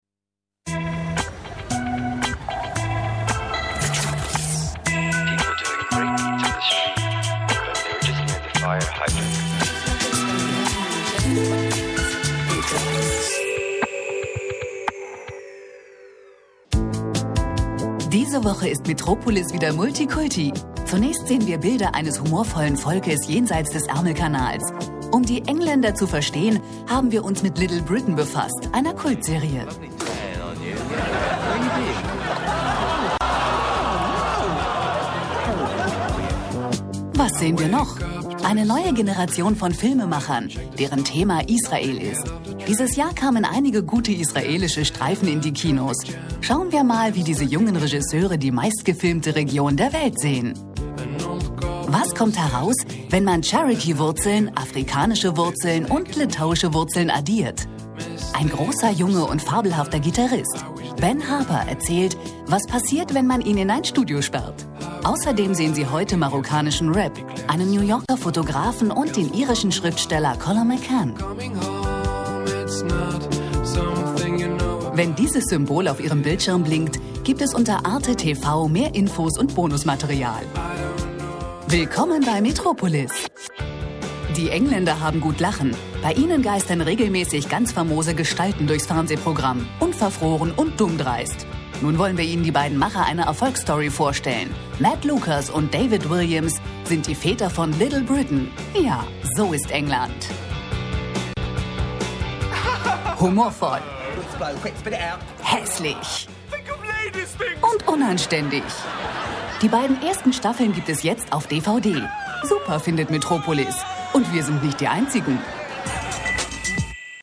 Flexible Stimme, breites Einsatzgebiet und große Spielfreude. mittlere Stimmlage facettenreich + flexibel Spaß + Spielfreude
Sprechprobe: Werbung (Muttersprache):